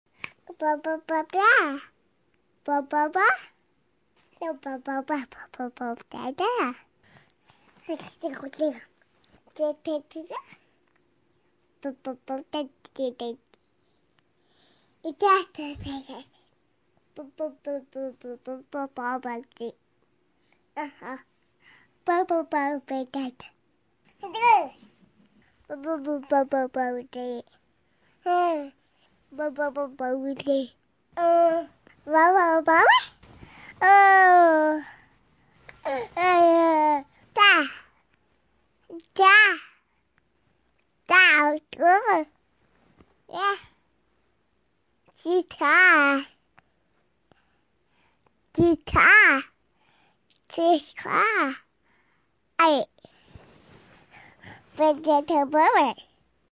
Download Free Baby Sound Effects
Baby